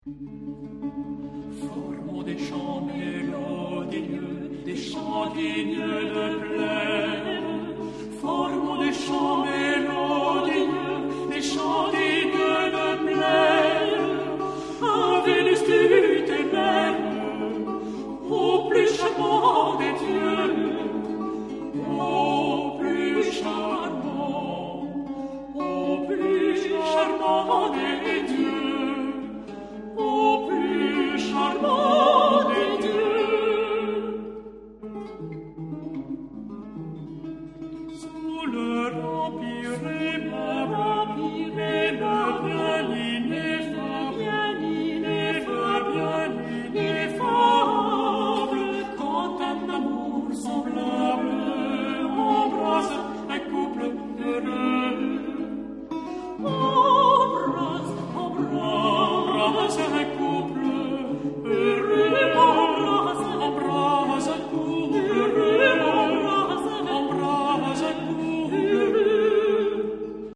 這裡收集了他為吉他主奏或伴奏所創作的樂曲，
不管是與人聲、小提琴或長笛的搭配，在今天聽來都相當的新鮮而且悅耳，
這張唱片中使用的三把吉他分別是製作於1790、1800及1810年的名琴，
錄音非常傑出，將這些名琴的共鳴與光澤感完全補抓無遺，
也清楚的表現出各樂器的位置與彼此間美妙的搭配效果。